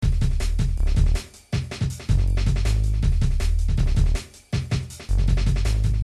Breakbeat Trance